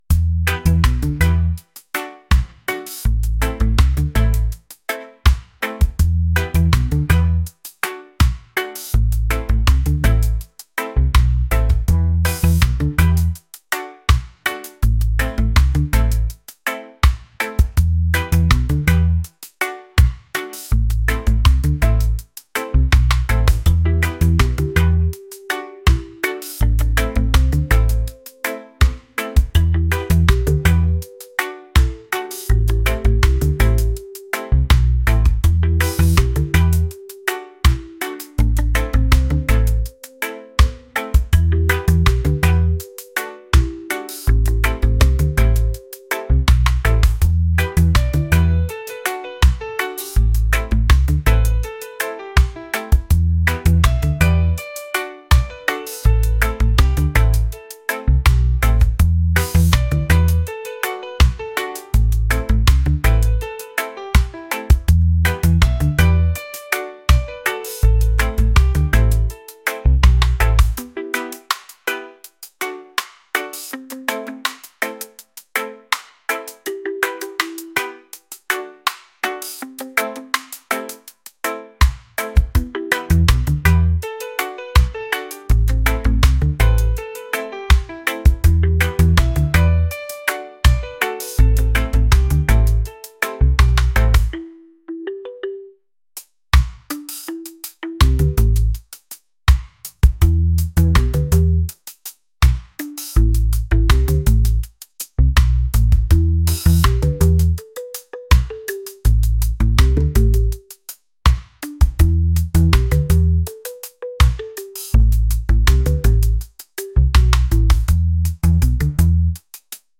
reggae | lofi & chill beats | acoustic